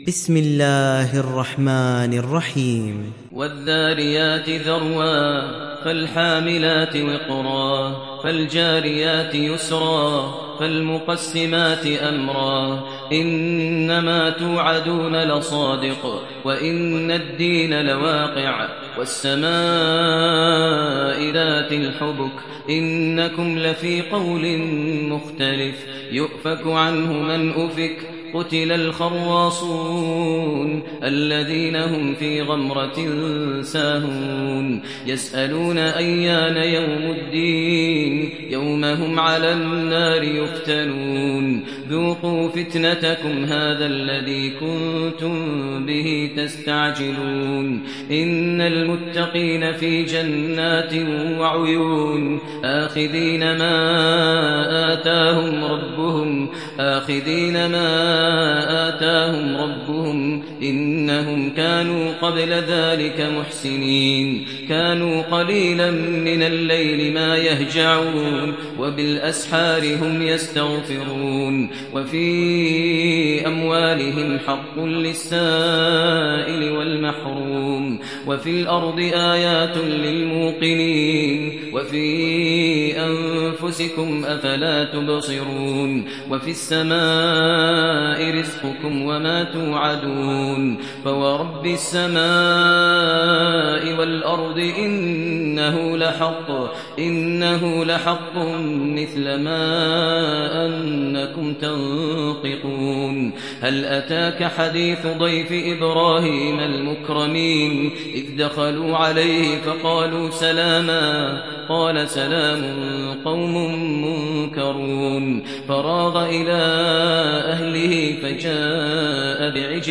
সূরা আয-যারিয়াত ডাউনলোড mp3 Maher Al Muaiqly উপন্যাস Hafs থেকে Asim, ডাউনলোড করুন এবং কুরআন শুনুন mp3 সম্পূর্ণ সরাসরি লিঙ্ক